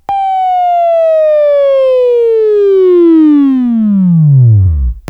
Sweeping Training program / Wavefile / Buzz